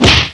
khanslap.wav